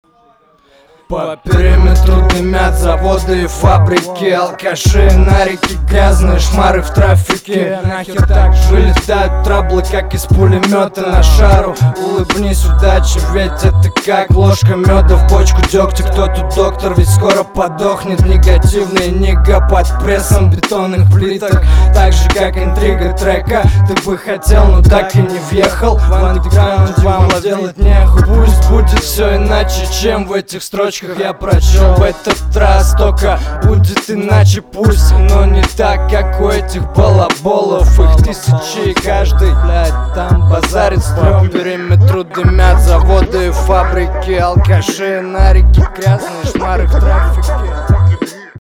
Скучный андер.
Текст неплох, читка хороша, но трек слушать скучно